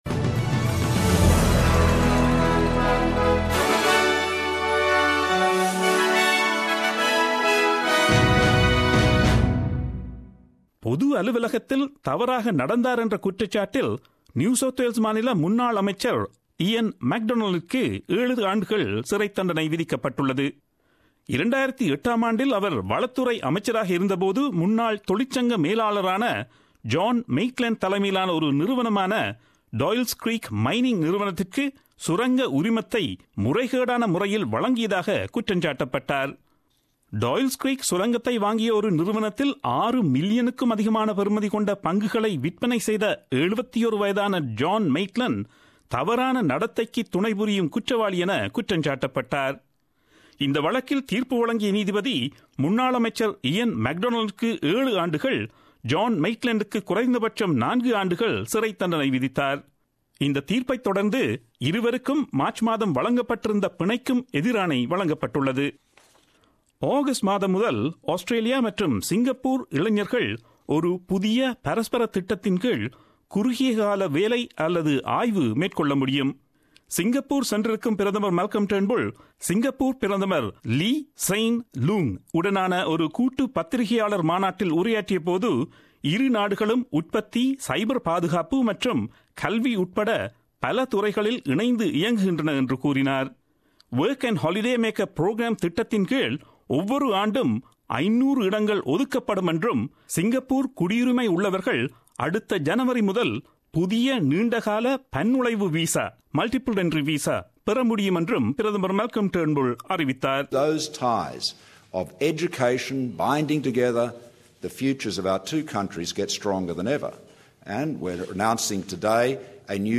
Australian news bulletin aired on Friday 02 June 2017 at 8pm.